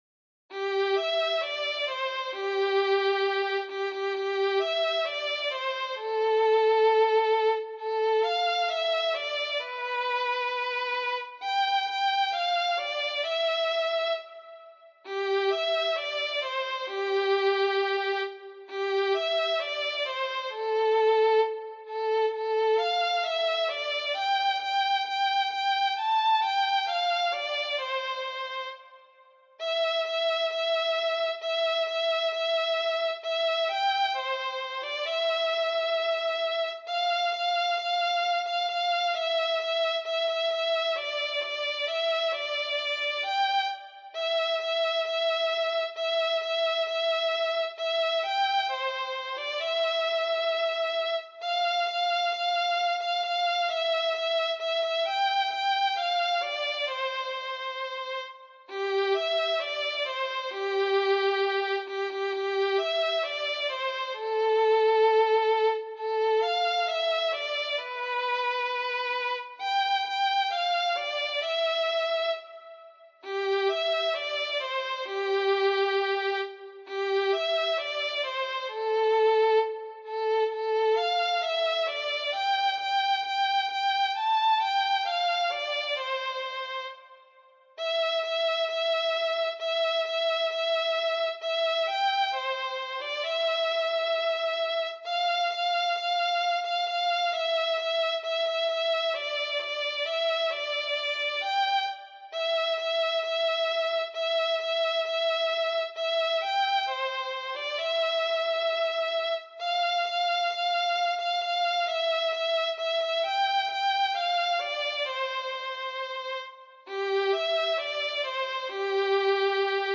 für Violine solo
für Violine solo, Noten und Text als pdf, Audio als mp3